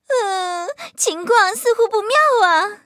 M2中坦中破语音.OGG